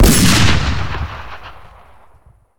SniperRifleShot.wav